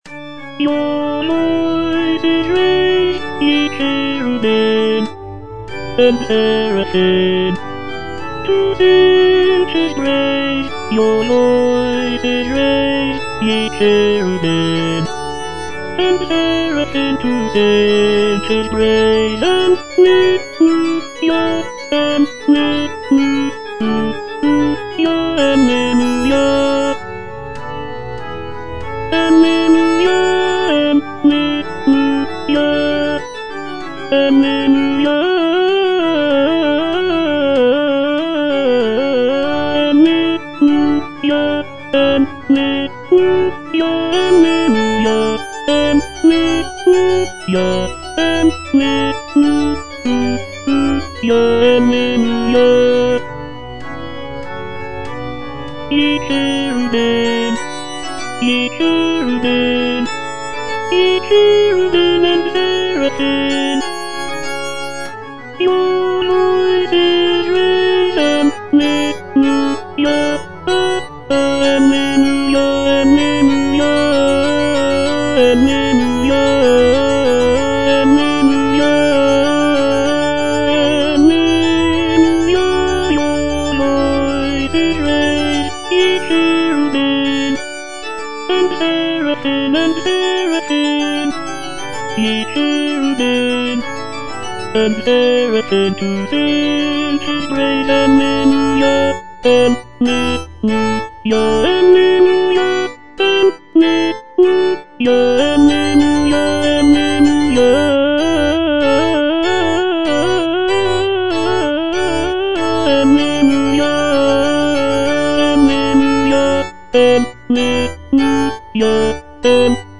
(A = 415 Hz)
Tenor (Voice with metronome) Ads stop